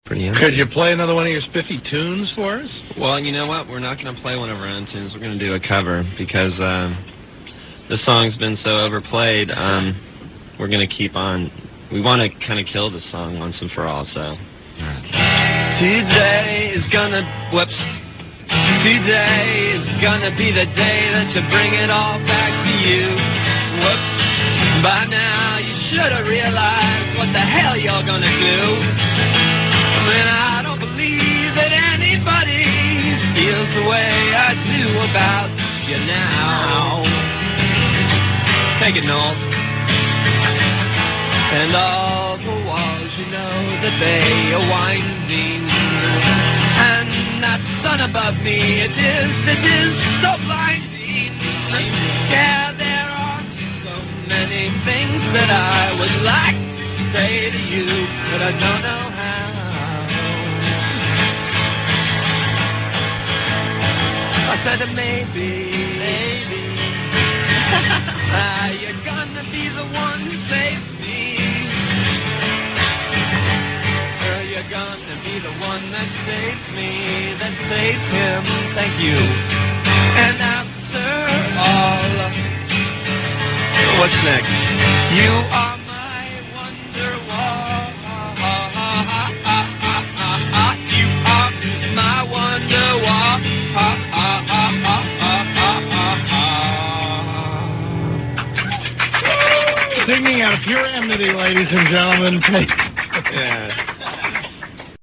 grabada en el programa de radio